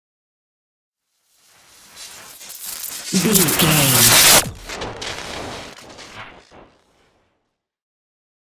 Appear sci fi electricity
Sound Effects
futuristic
high tech
whoosh